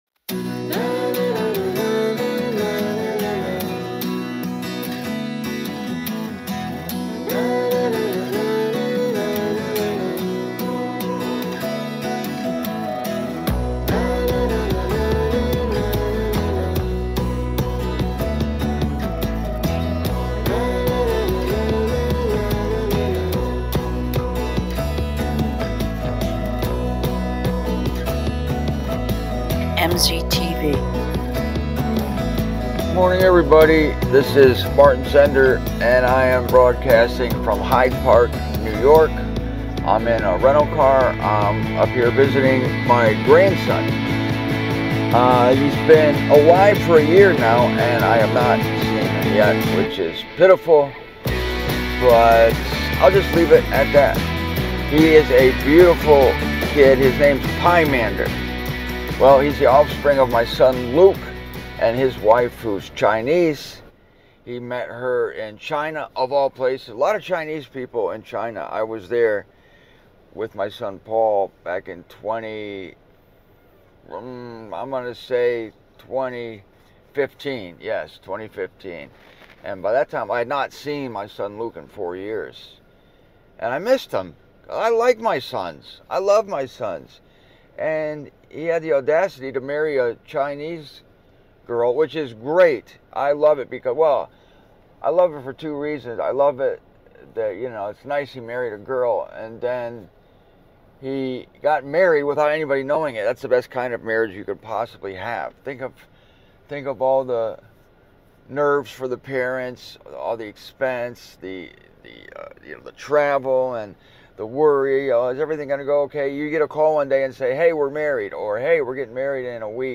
The answer to that question and other spiritual queries is explored from my rental car this morning here on MZTV.